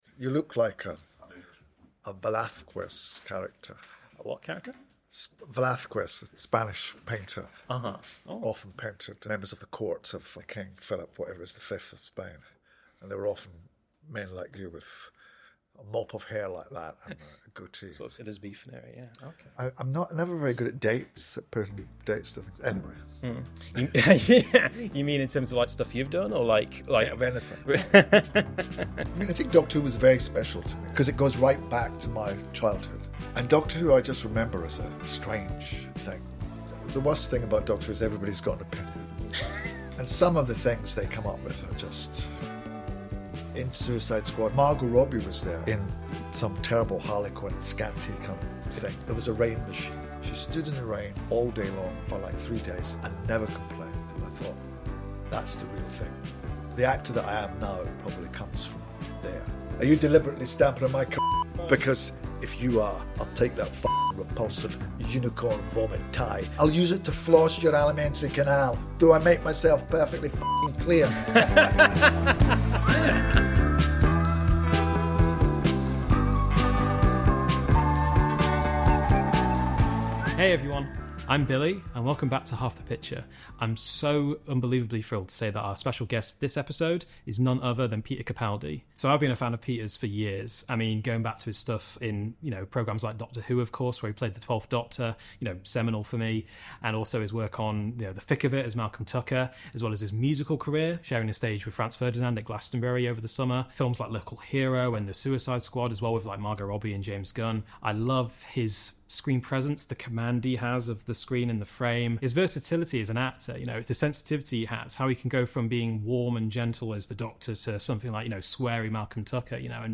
Guest Peter Capaldi